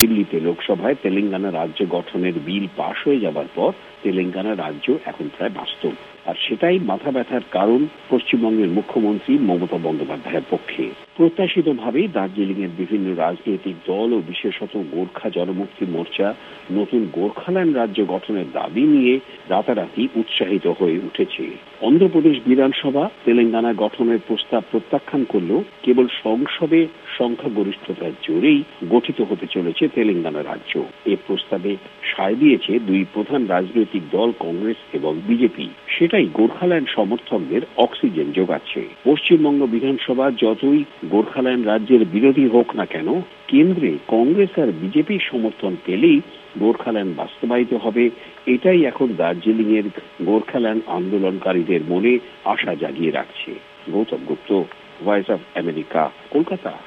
বুধবার: কলকাতা সংবাদদাতাদের রিপোর্ট